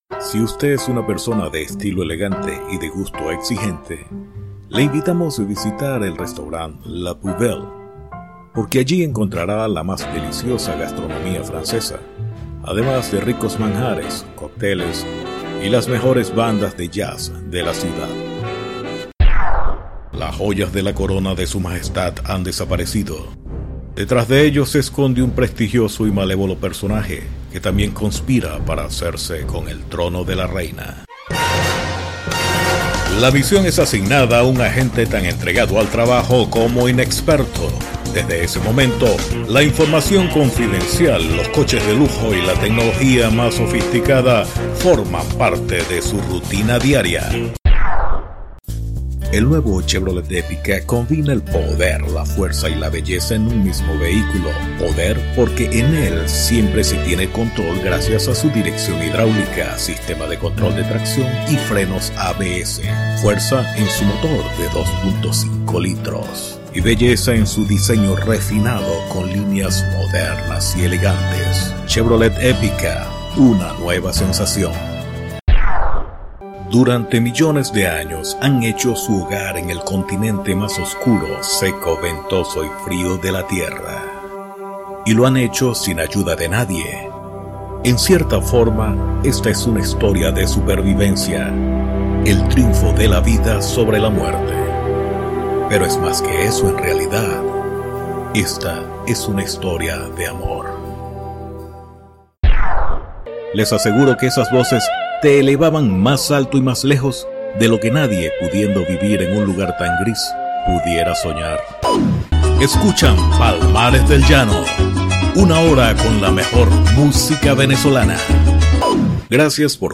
Kein Dialekt
Sprechprobe: Werbung (Muttersprache):
I am announcer certified by The University Central of Venezuela.